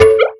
player_ping.wav